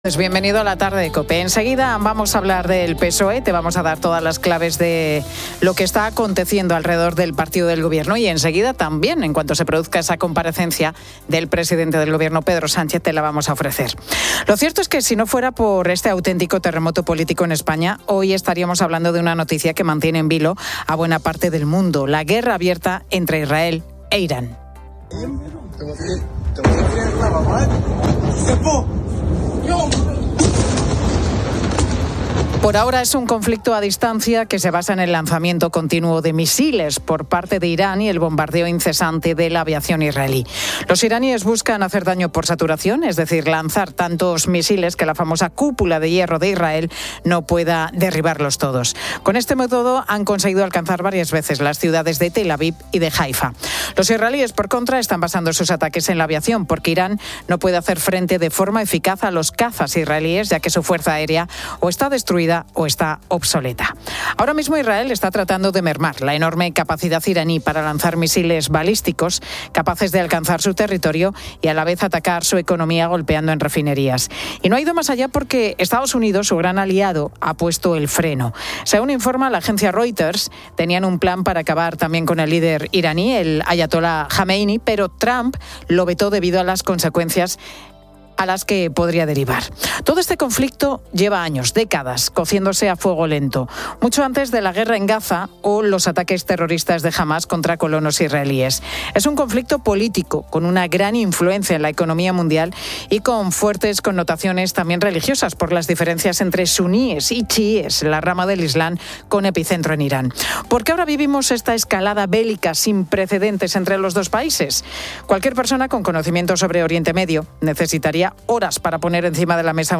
La Tarde 16:00H | 16 JUN 2025 | La Tarde Pilar García Muñiz y el equipo de La Tarde siguen en directo la comparecencia del presidente del Gobierno, Pedro Sánchez.